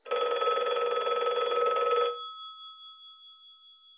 Ring.wav